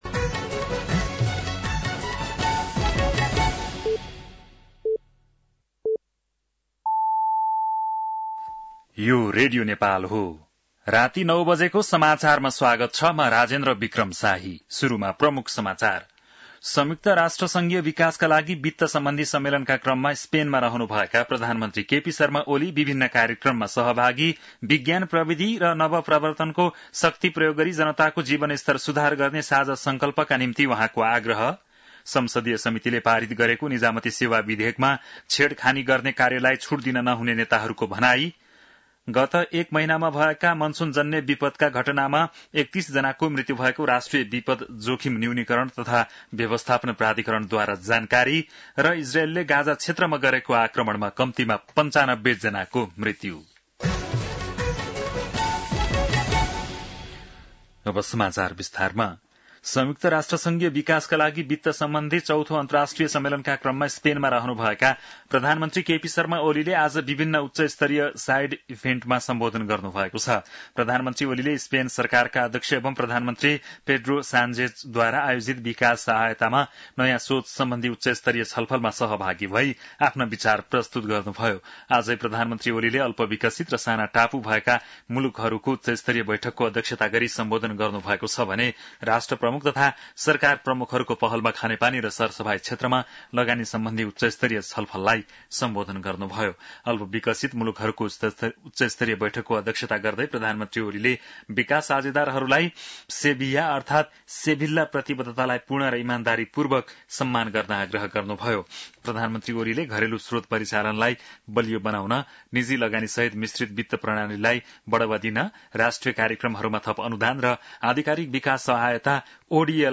बेलुकी ९ बजेको नेपाली समाचार : १७ असार , २०८२